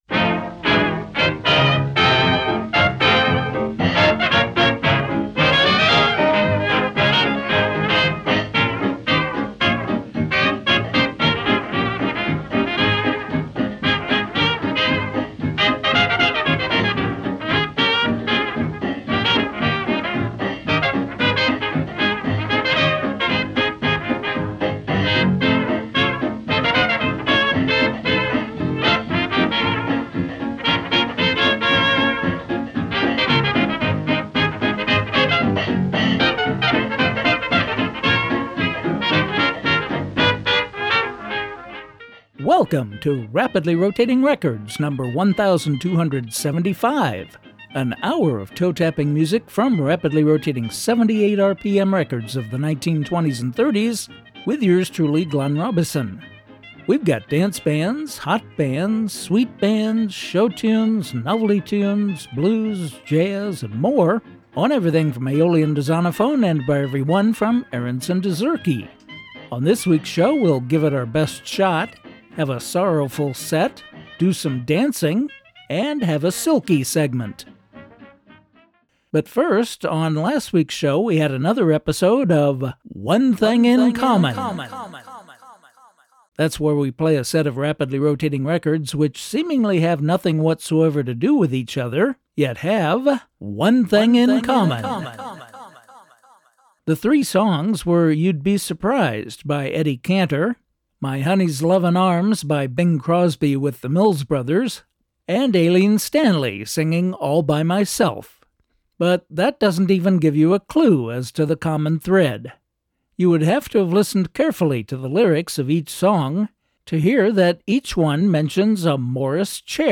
bringing you vintage music to which you can’t not tap your toes, from rapidly rotating 78 RPM records of the 1920s and ’30s.